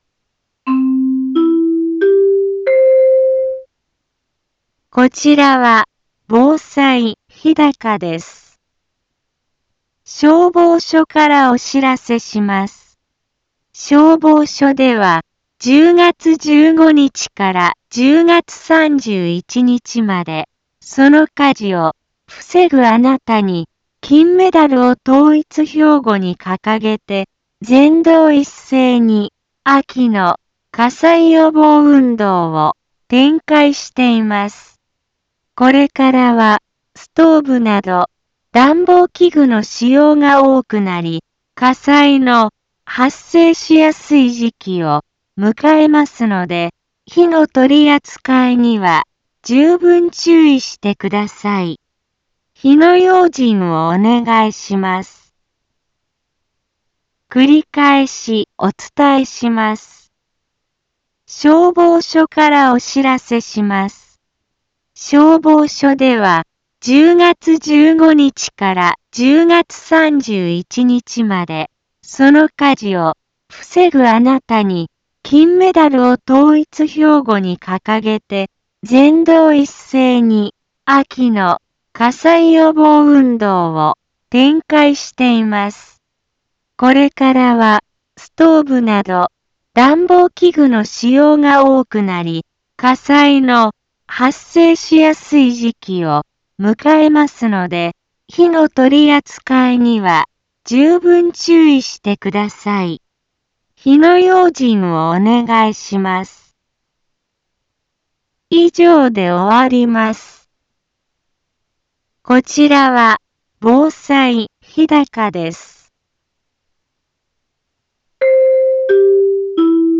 Back Home 一般放送情報 音声放送 再生 一般放送情報 登録日時：2020-10-15 10:04:17 タイトル：秋の火災予防運動について インフォメーション：消防署からお知らせします。